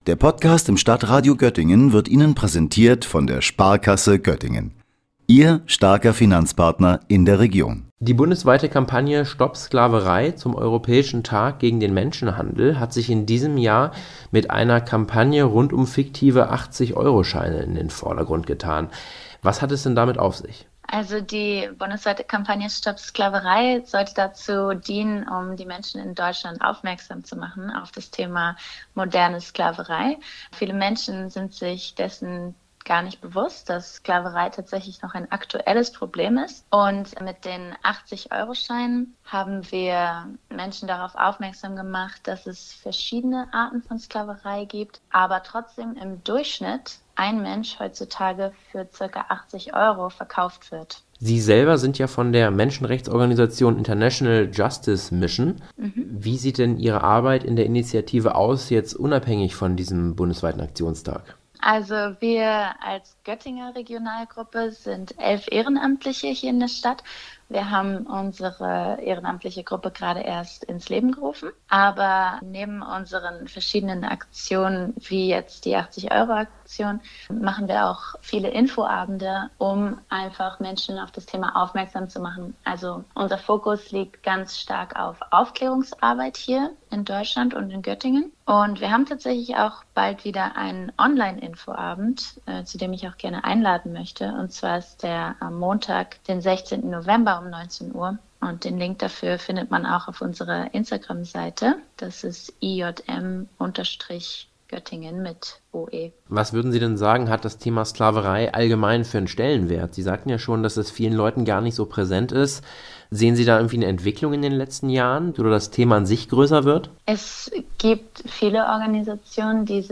„Stopp Sklaverei“ war der plakative Titel zum europäischen Tag gegen den Menschenhandel am 18. Oktober.